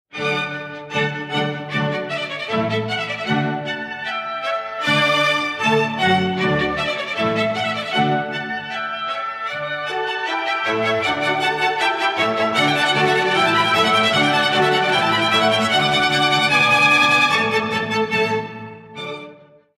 Presto